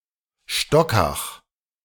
Stockach (German pronunciation: [ˈʃtɔkax]
De-Stockach.ogg.mp3